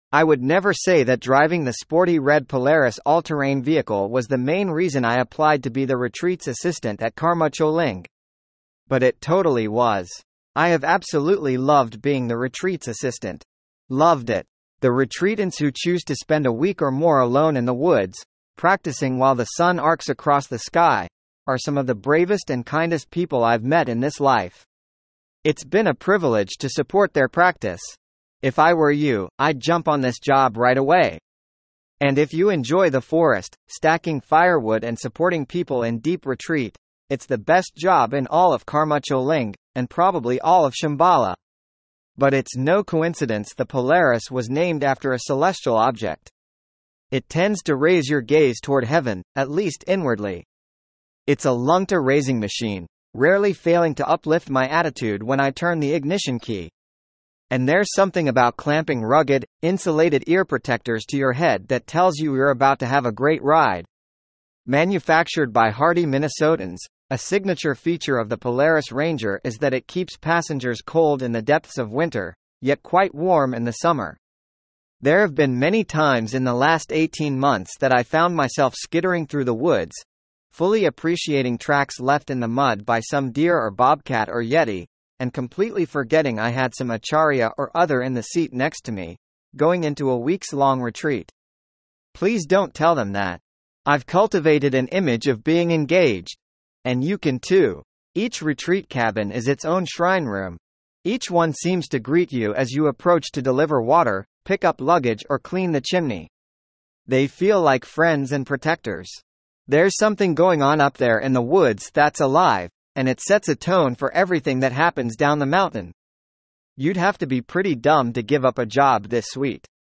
text-to-speech